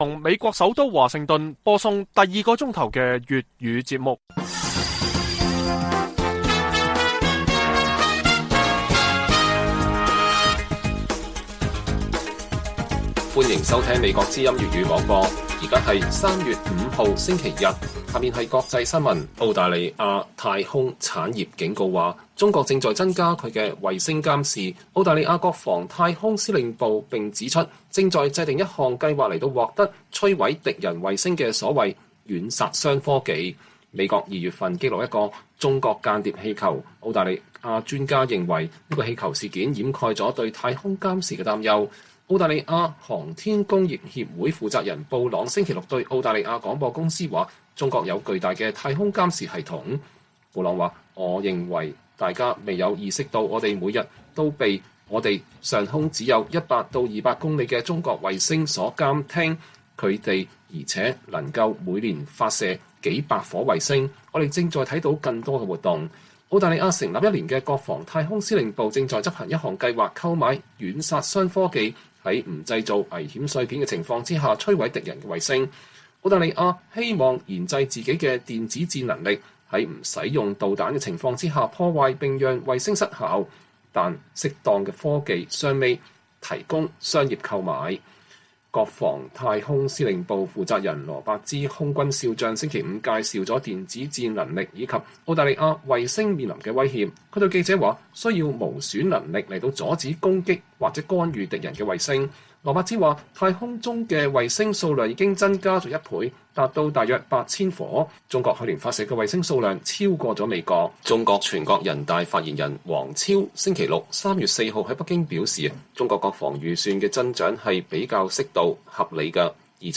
粵語新聞 晚上10-11點 ：美國陸軍部長：不認為北京侵台迫在眉睫但須做好準備